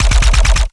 Added more sound effects.
GUNAuto_RPU1 C Burst_02_SFRMS_SCIWPNS.wav